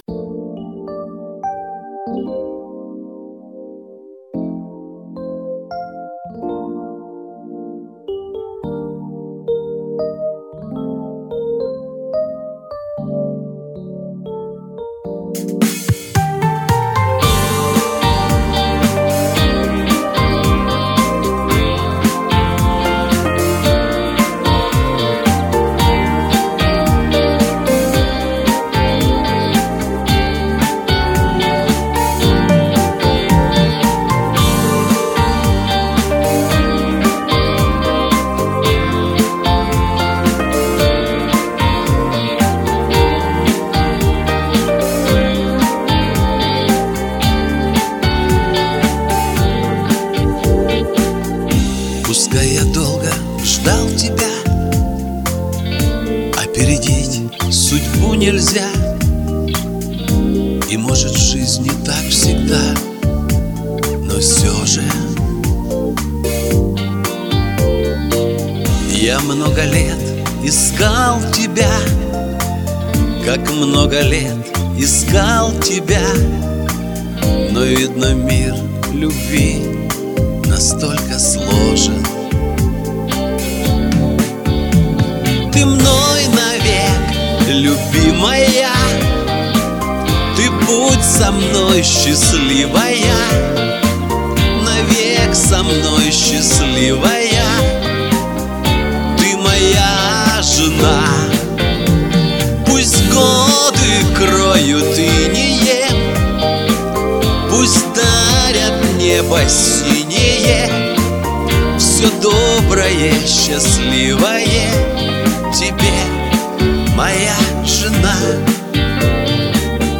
у Вас на припевах или немножко зажат голос